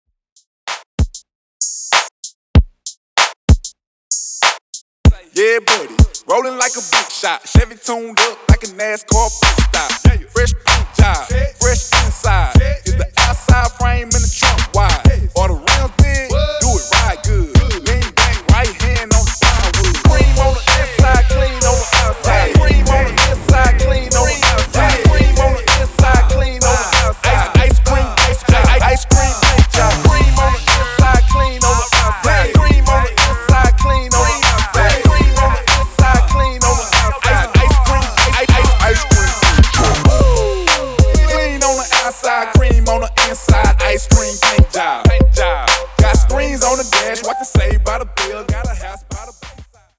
Genres: 2000's , R & B , RE-DRUM
Clean BPM: 96 Time